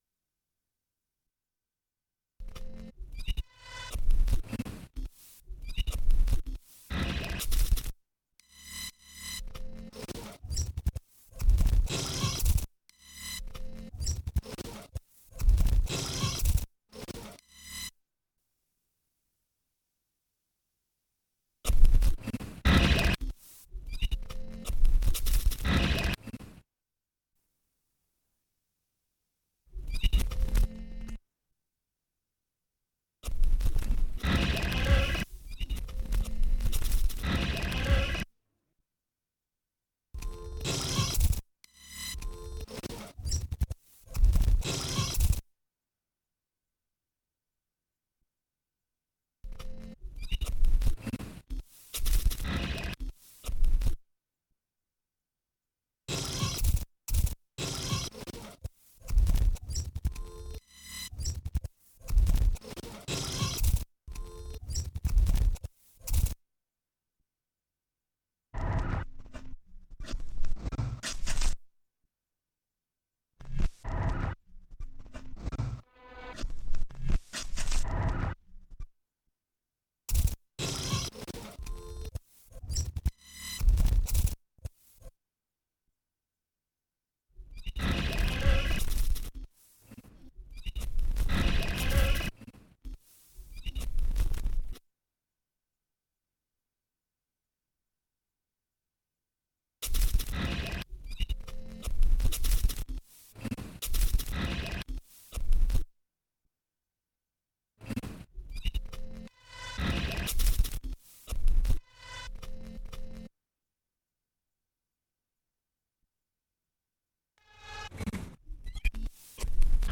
Digitalisiert von MiniDisk.